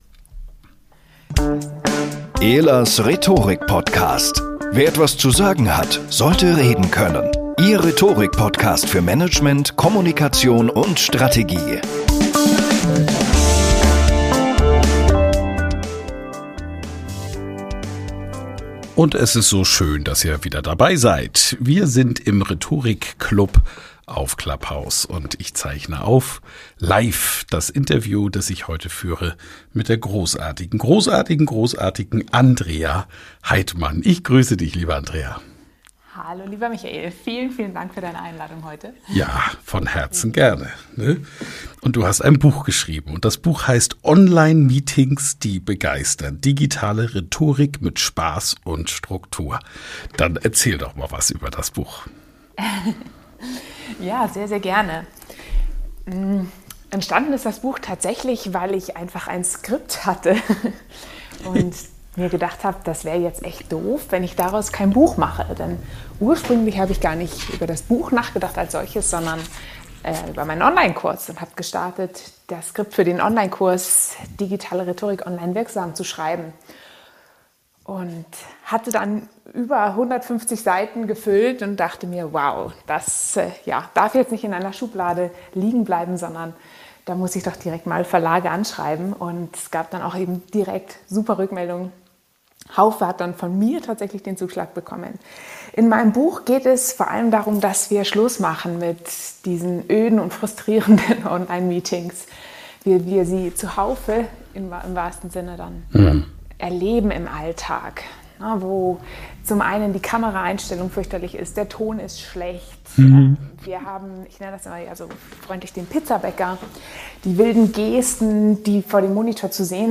Live in CLUBHOUSE aufgezeichnet